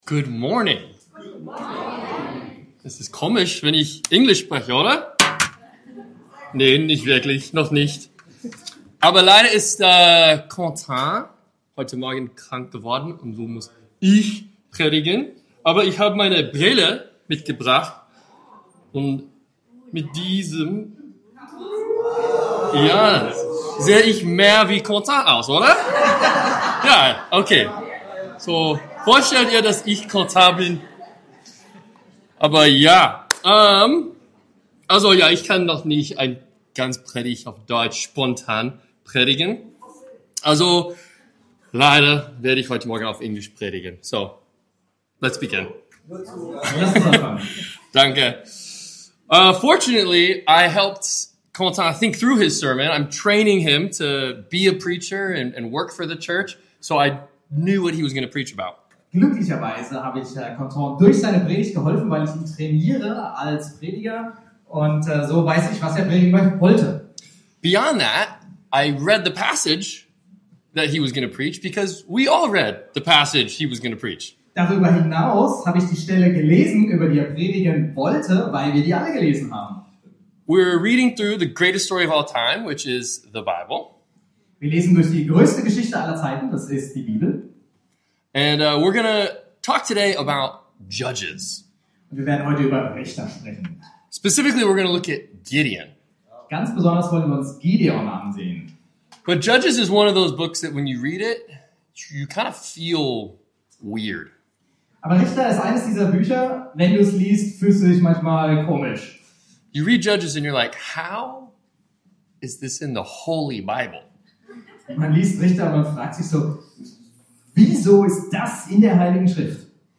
Gott allein ist König (mit Link zum Video) ~ BGC Predigten Gottesdienst Podcast